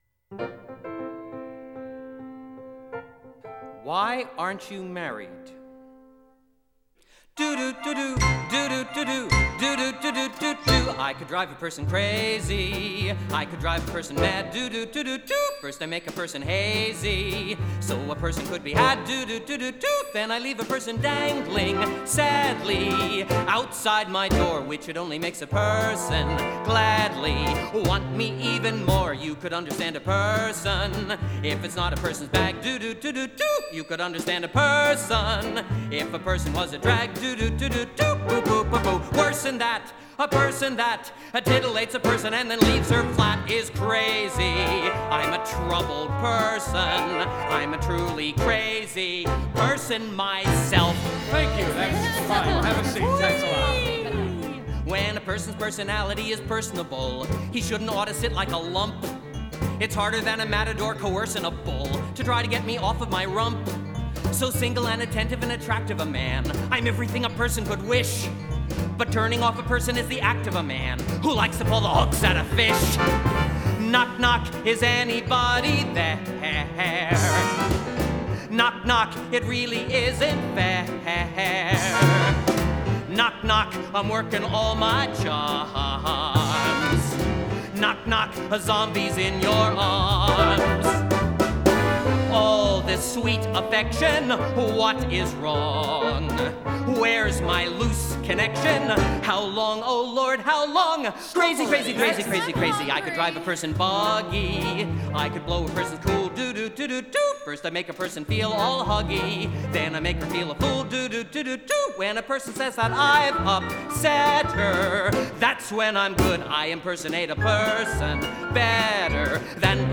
I just like it because of its energy and zaniness.